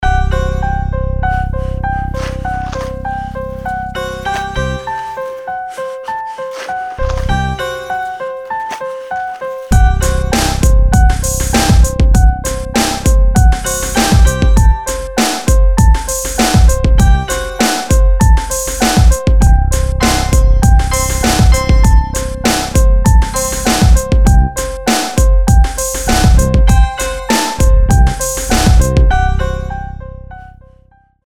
full mix